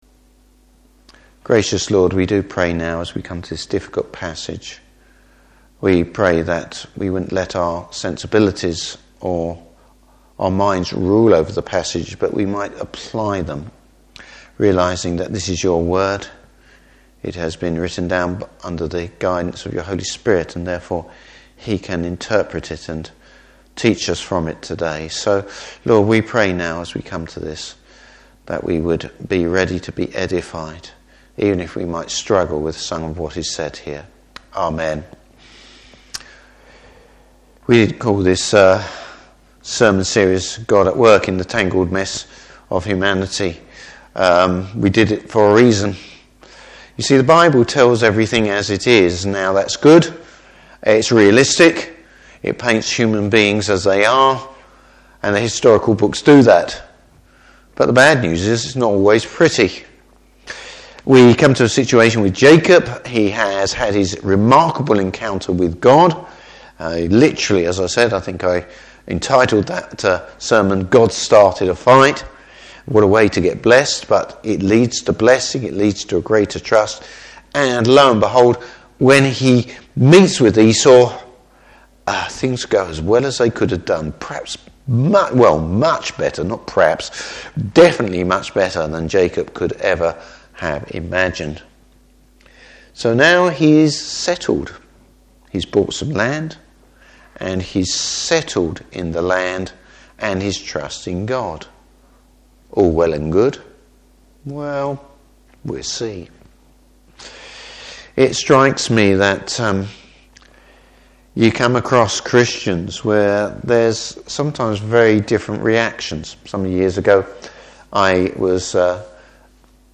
Service Type: Evening Service Where’s God in the reckoning?